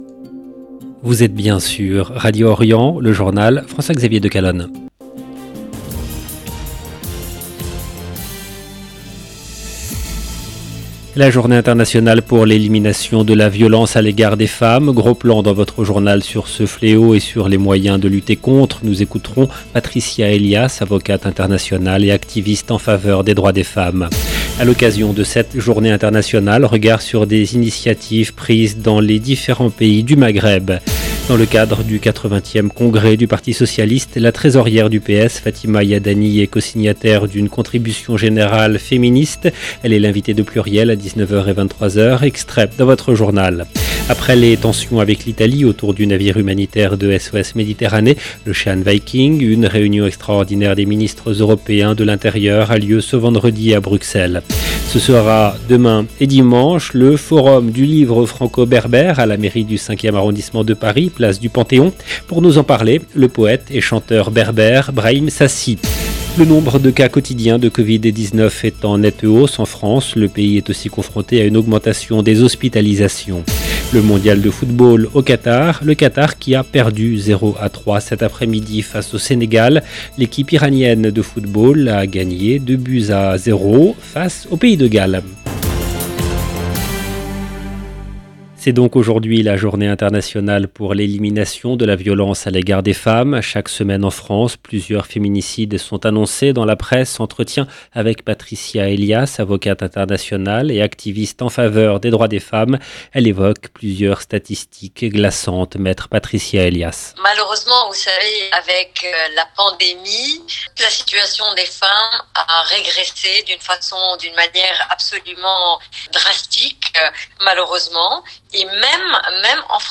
LE JOURNAL DU SOIR EN LANGUE FRANCAISE DU 25/11/22